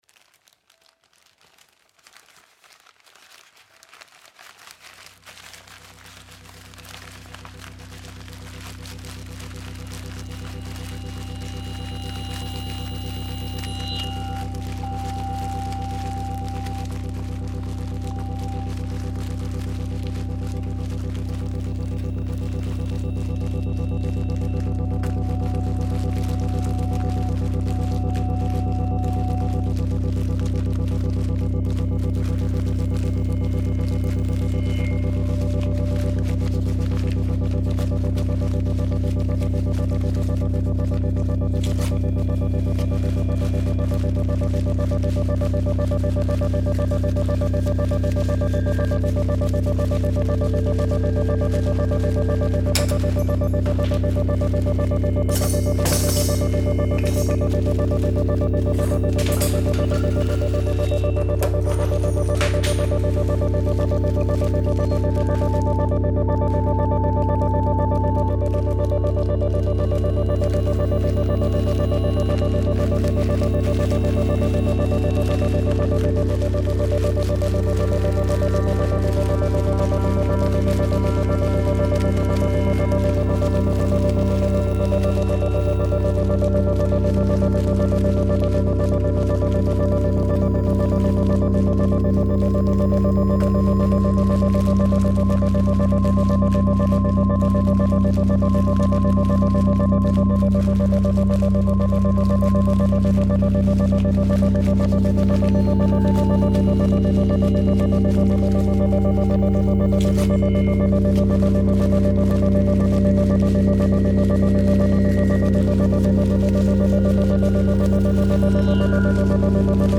Synthesizers
Guitar + Computer